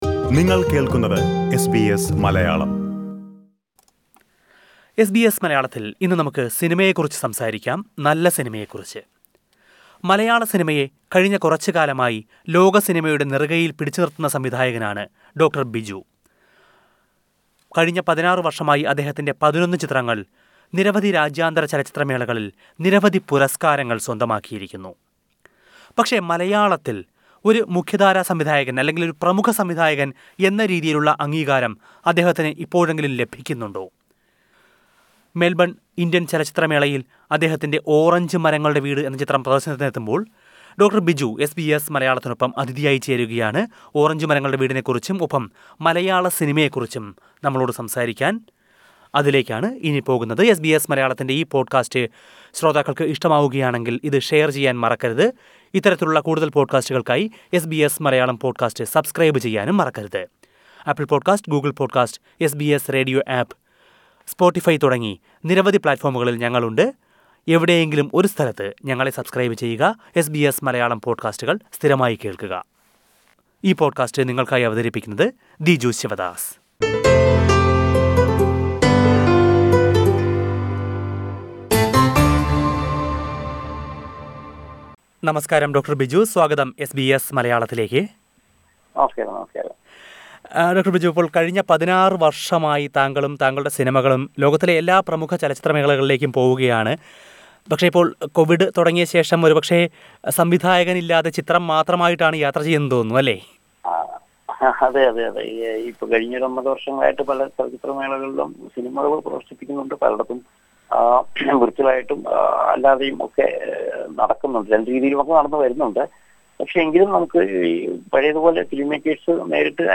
സമീപകാലത്തായി മലയാളസിനിമയെ ലോകസിനിമാരംഗത്ത് സജീവമാക്കി നിർത്തുന്ന സംവിധായകനാണ് ഡോ. ബിജു. മെൽബൺ ഇന്ത്യൻ ചലച്ചിത്രമേളയിൽ അദ്ദേഹത്തിന്റെ ‘ഓറഞ്ചുമരങ്ങളുടെ വീട്’ പ്രദർശനത്തിനെത്തുമ്പോൾ, അദ്ദേഹം എസ് ബി എസ് മലയാളവുമായി സംസാരിക്കുന്നു.